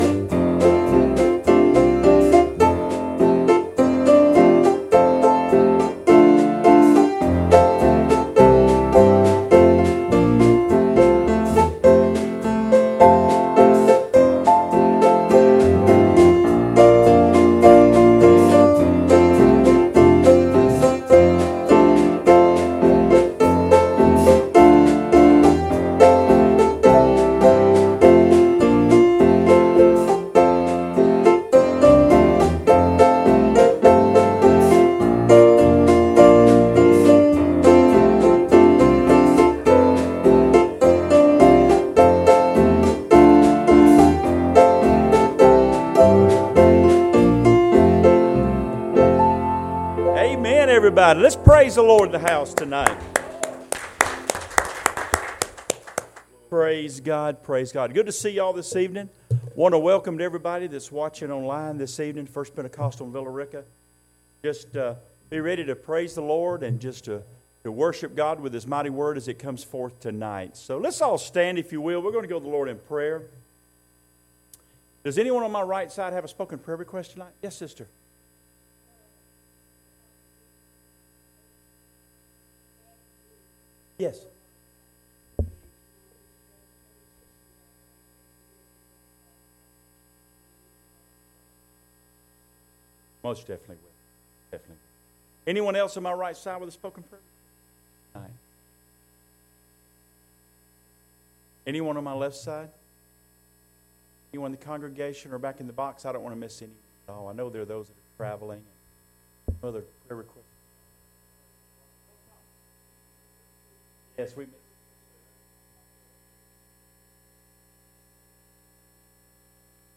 Service Type: Wednesday Evening Services